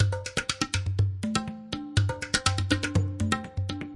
印度 " 桶装塑料落在坚硬的表面上砰砰作响 印度
描述：在硬质表面上的塑料桶塑料在offmic India.flac上砰的一声
Tag: 轰的一声 下来 塑料 表面 印度